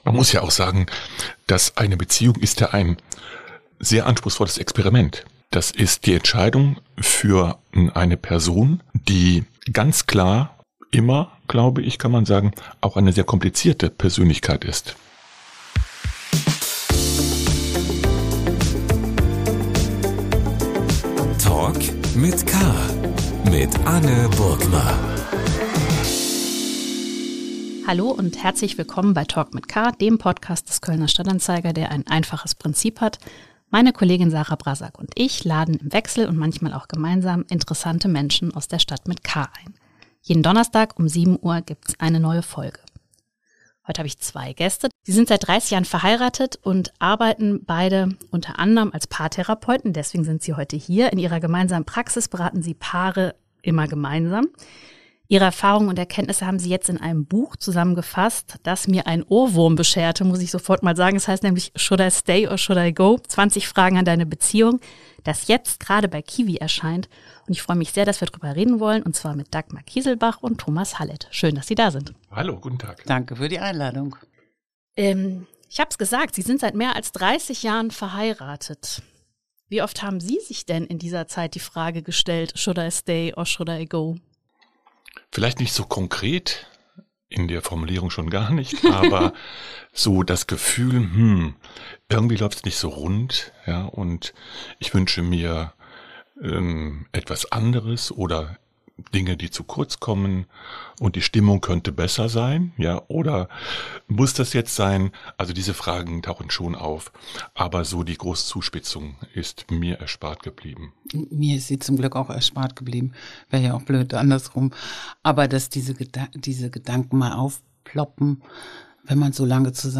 Die Paartherapeuten im Gespräch. ~ Talk mit K - der Talk-Podcast des Kölner Stadt-Anzeiger Podcast